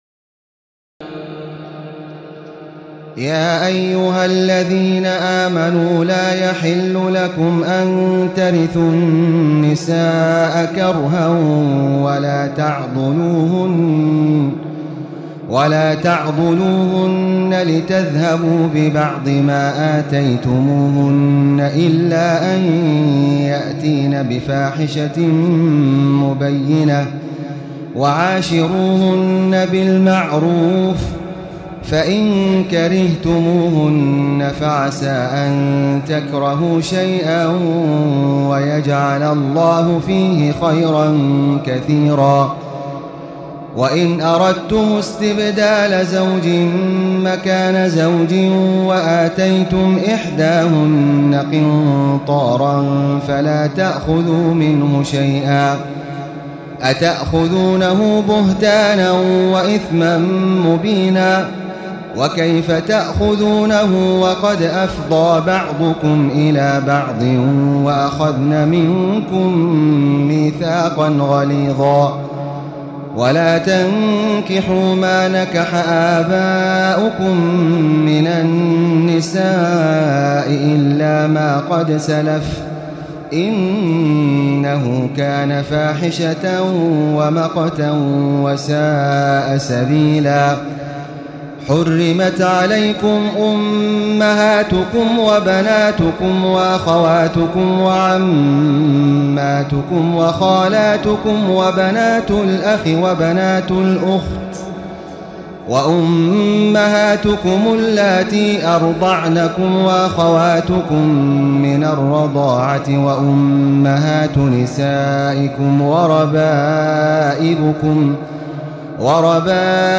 تراويح الليلة الرابعة رمضان 1436هـ من سورة النساء (19-87) Taraweeh 4 st night Ramadan 1436H from Surah An-Nisaa > تراويح الحرم المكي عام 1436 🕋 > التراويح - تلاوات الحرمين